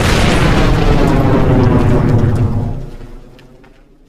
nuke_explode.4.ogg